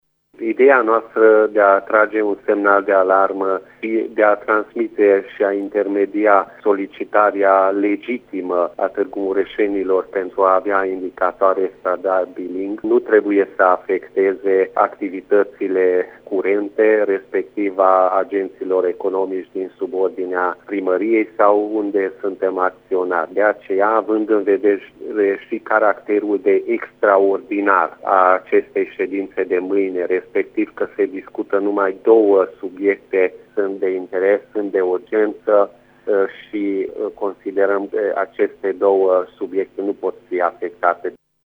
Liderul UDMR Tîrgu-Mureş, viceprimarul Peti Andras, a declarat că decizia de a nu boicota şedinţa a fost luată deoarece cele două proiecte sunt importante pentru oraş: